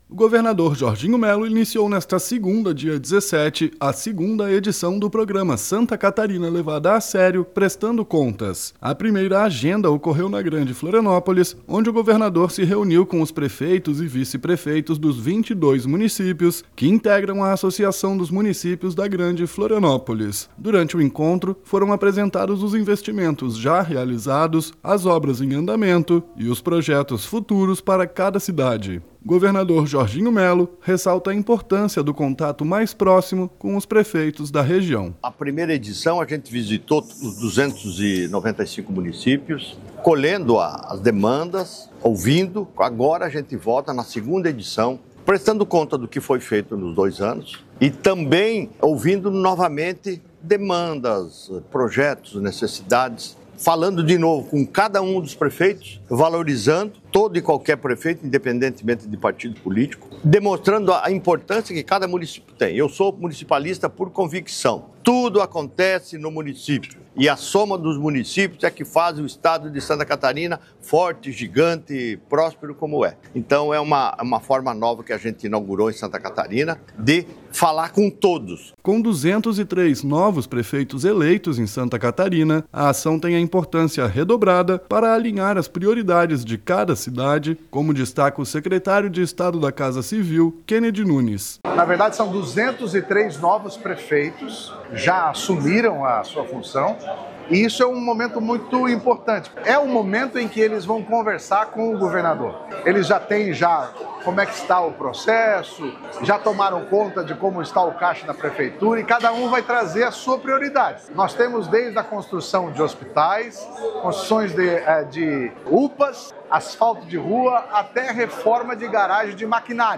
BOLETIM – Programa SC Levada a Sério: governador apresenta investimentos e alinha prioridades com os 22 prefeitos da Grande Florianópolis
O governador Jorginho Mello ressalta a importância do contato mais próximo com os prefeitos da região:
Com 203 novos prefeitos eleitos em Santa Catarina, a ação tem importância redobrada para alinhar as prioridades de cada cidade, como destaca o secretário de Estado da Casa Civil, Kennedy Nunes: